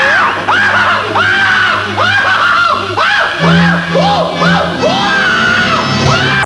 Bugs screaming